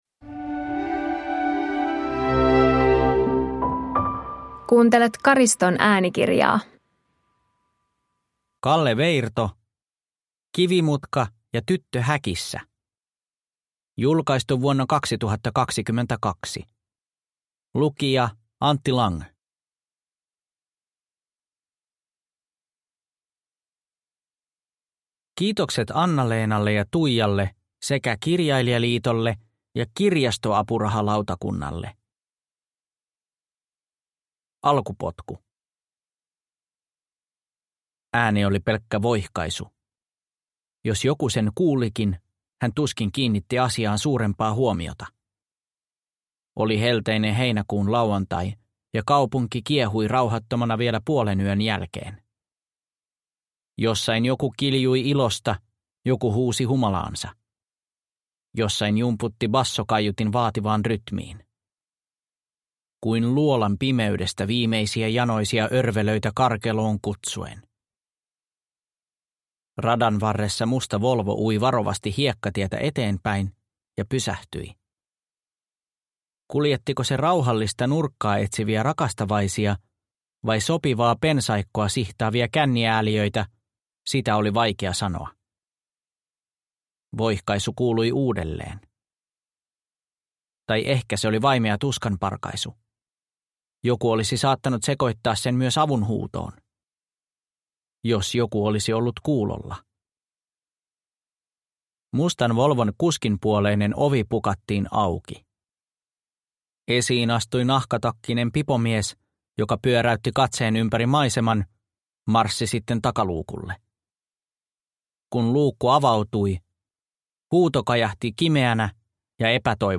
Kivimutka ja tyttö häkissä – Ljudbok